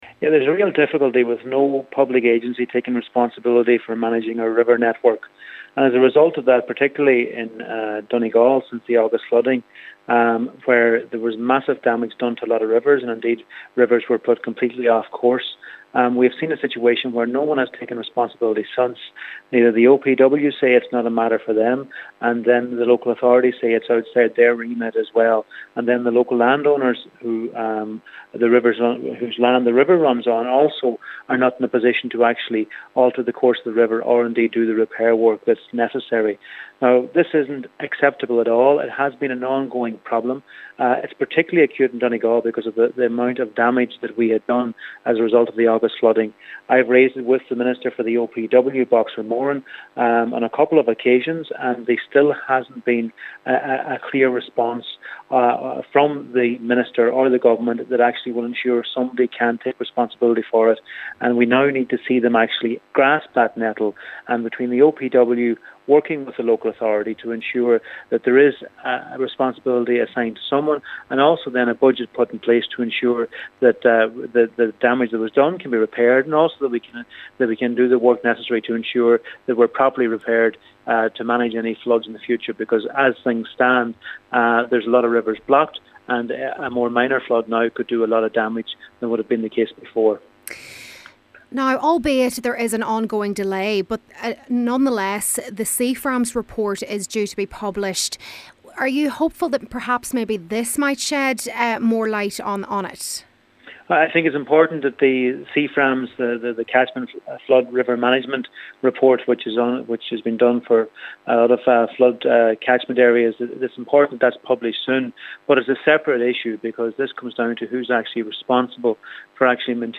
He says a National body is needed to address the issue: